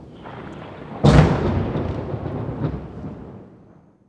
THUNDER 2.WAV